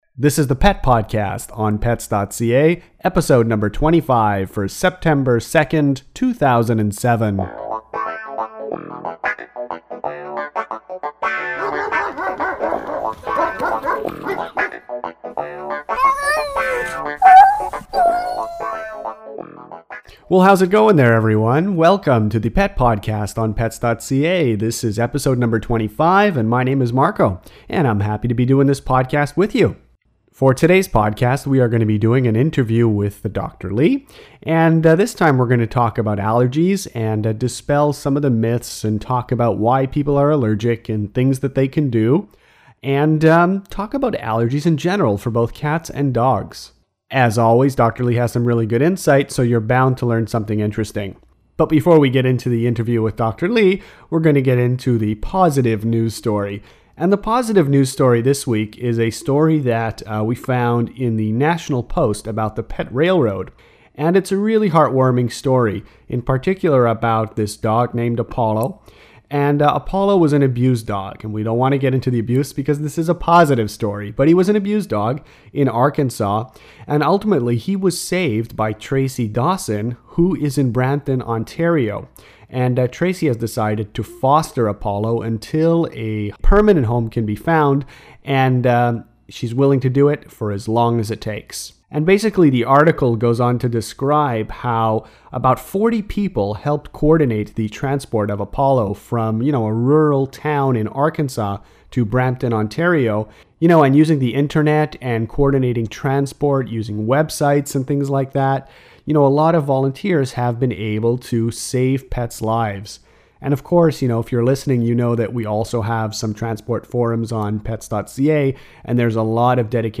Allergies to dogs and cats – Pet podcast #25 – Interview